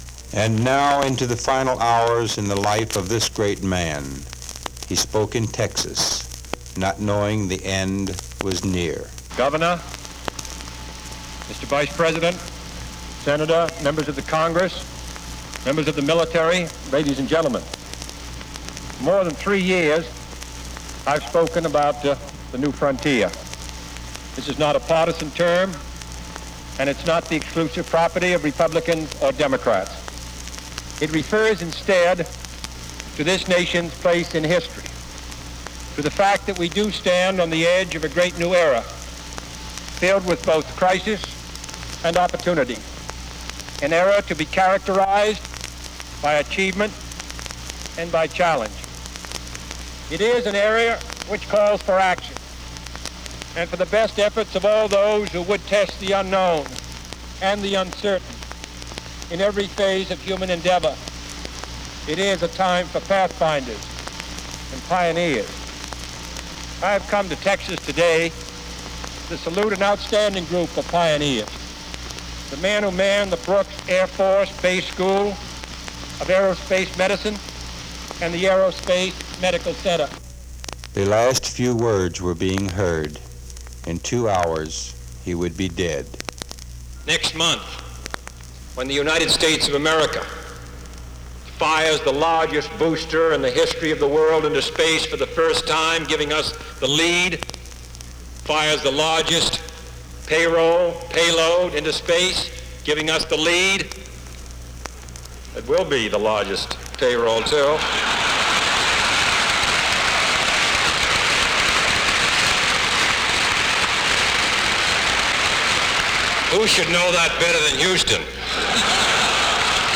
U.S. President John F. Kennedy gives a speech on space flight